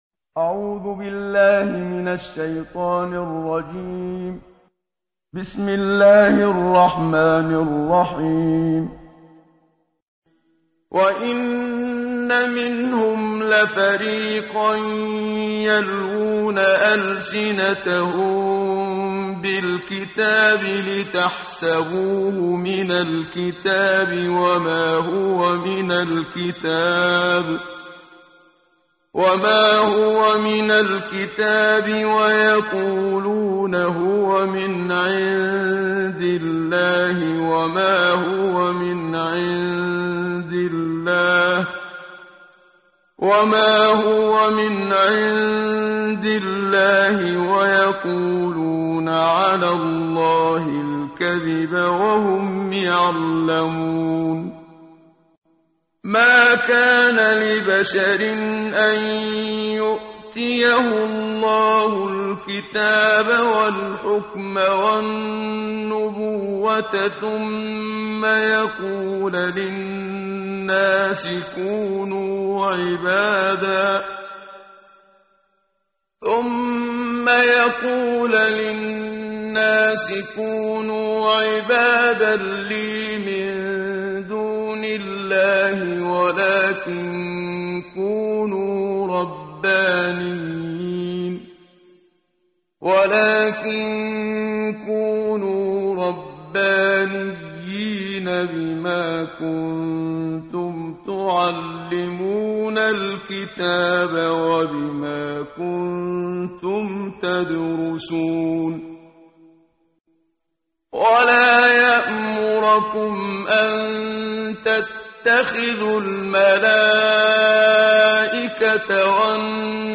قرائت قرآن کریم ، صفحه 60، سوره مبارکه آلِ عِمرَان آیه 78 تا 83 با صدای استاد صدیق منشاوی.